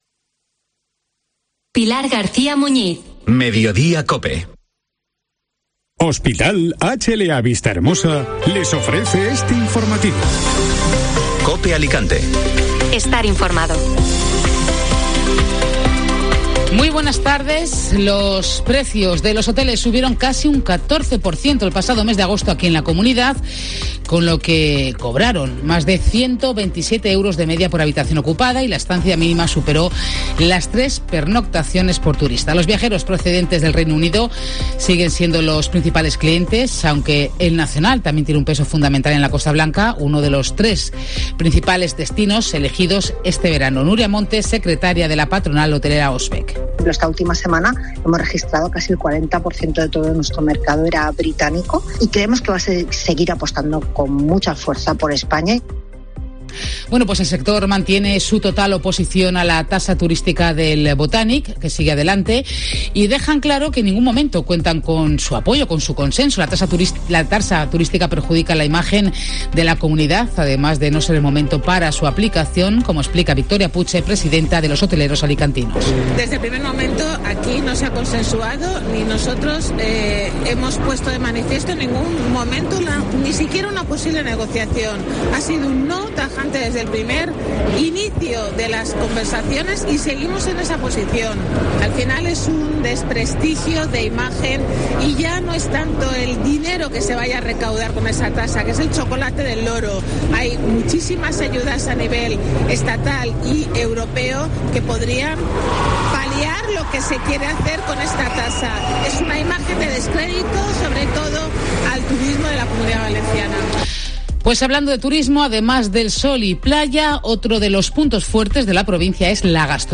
AUDIO: Escucha las noticias de este viernes y los primeros sonidos de la inauguración de Alicante Gastronómica